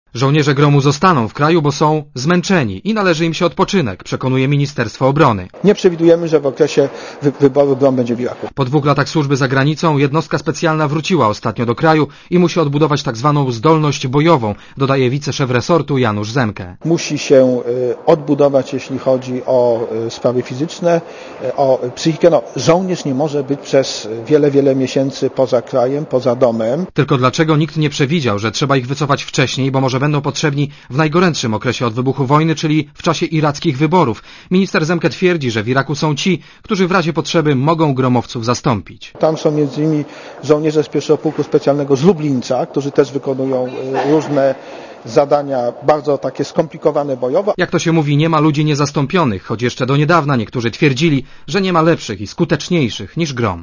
Źródło zdjęć: © PAP 06.01.2005 | aktual.: 06.01.2005 14:25 ZAPISZ UDOSTĘPNIJ SKOMENTUJ Relacja reportera Radia ZET